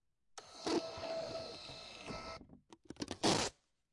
收银机 " 收银机打印'n' Rip A1
描述：这是一个现代便携式收银机打印每日报告的记录。然后纸带被撕开了。 由于纸带变长并产生不同频率的共鸣，所以有一些有趣的频率效果。这些声音没有经过任何处理。 录音是用手持式Zoom H2的后置话筒录制的。原本是96kHz/24位的WAV。 用Audacity编辑并转换为FLAC.
Tag: 敲竹杠 电子 打印 技工 磁带 直到 撕裂 电动 打印机 马达